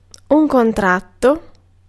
Ääntäminen
IPA : /diːd/